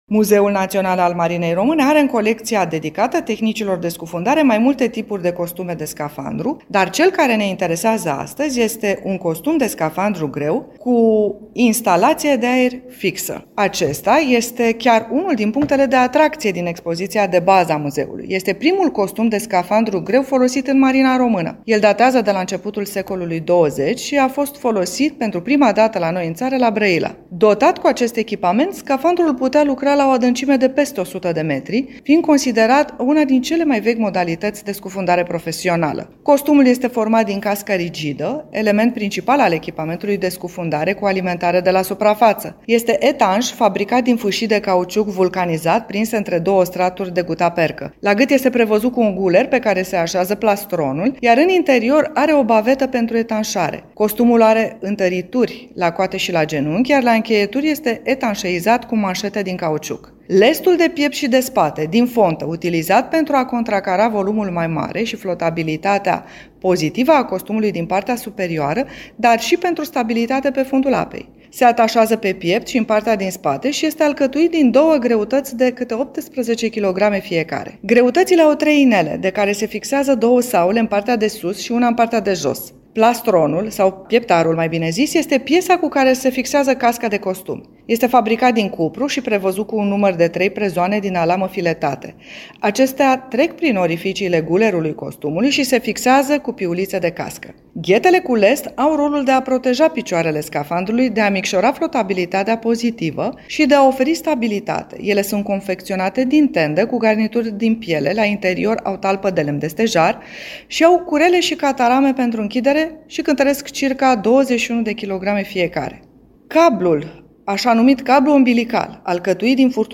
Specialistul
în dialog